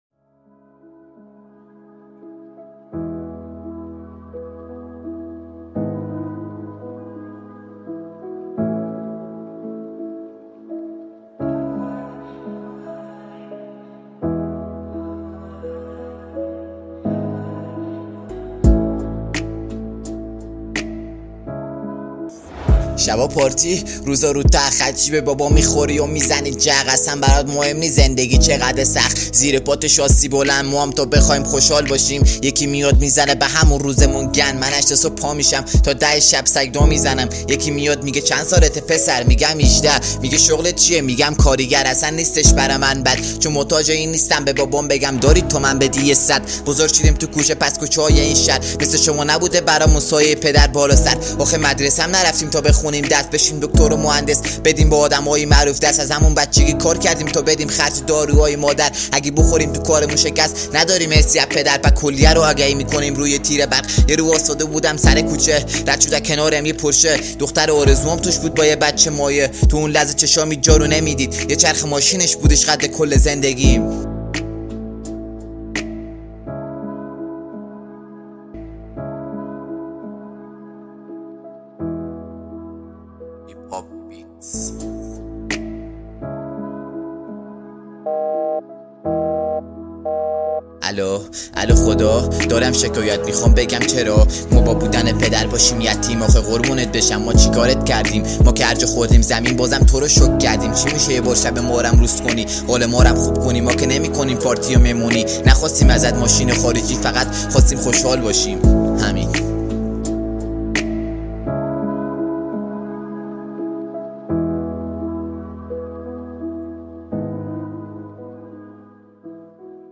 رپفارس رپ